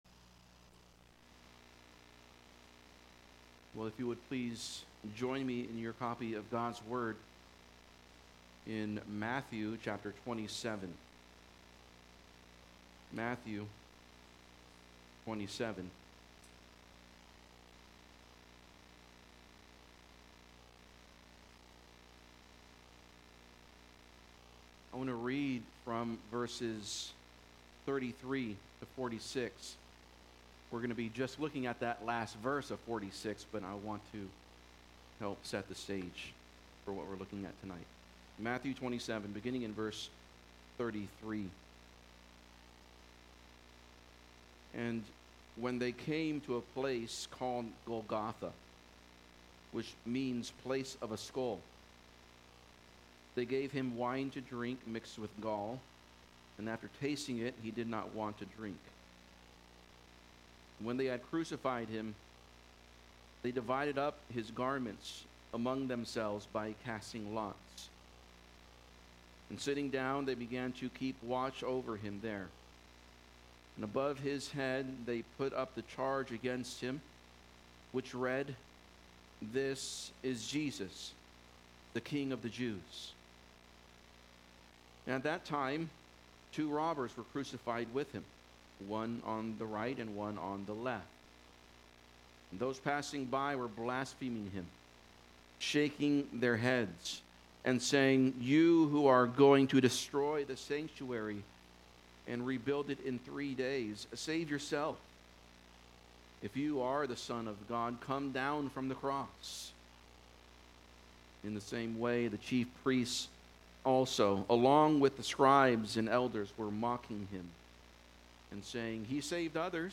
GOOD FRIDAY SERMON | God Forsaken of God | Redeemer Bible Church